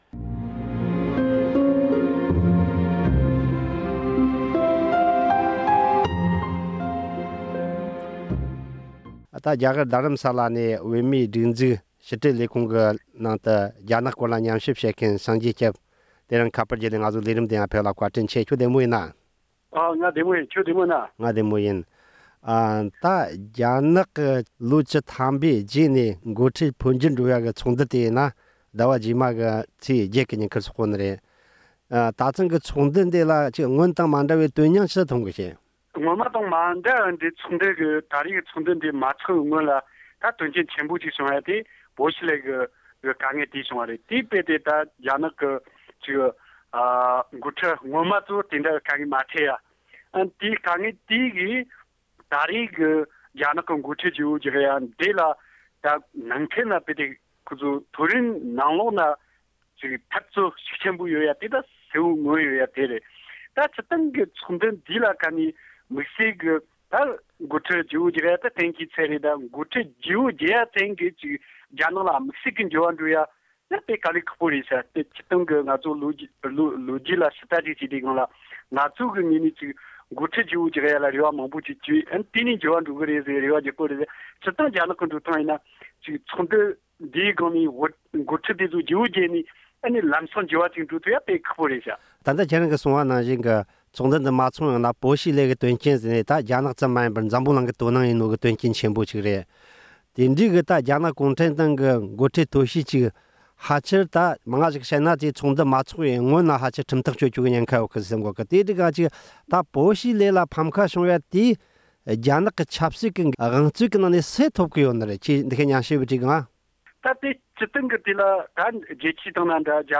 གླེང་མོལ།